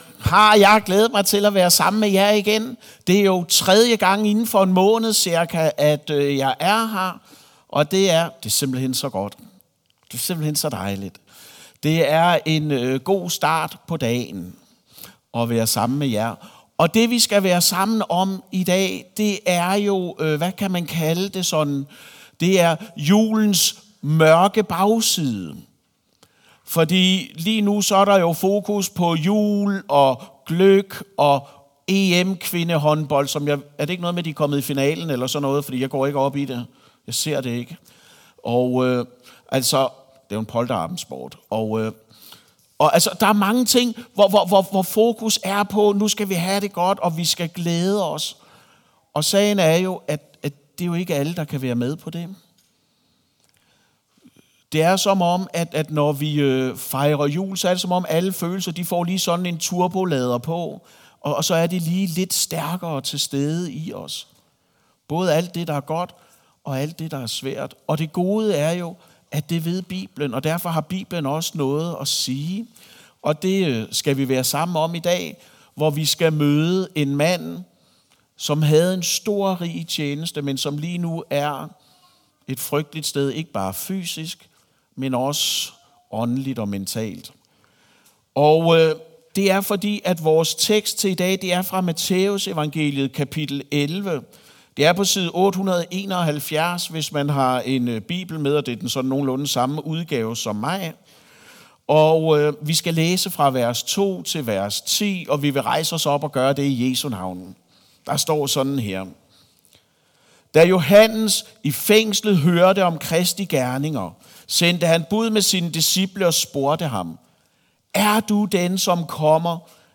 Gudstjeneste | Aalborg Frimenighed